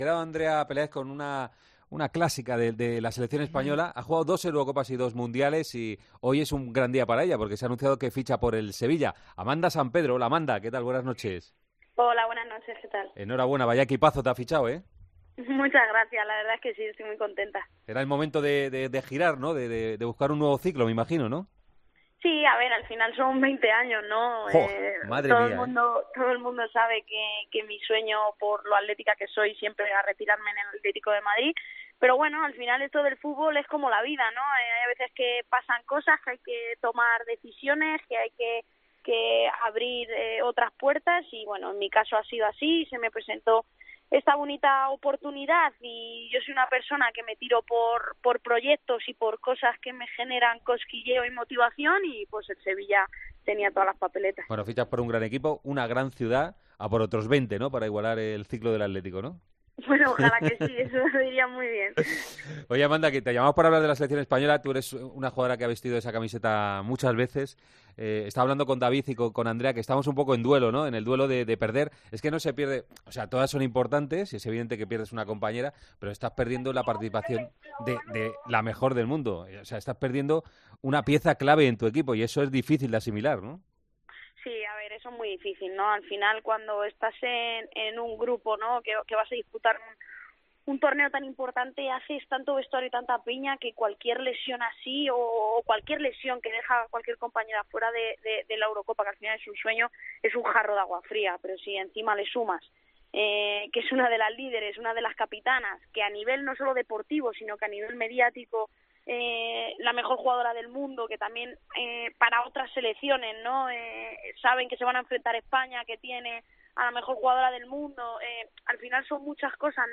Hablamos con la futbolista española, que hoy ha sido presentada como nueva jugador del Sevilla, sobre las opciones de España en la Eurocopa y la lesión de Alexia Putellas.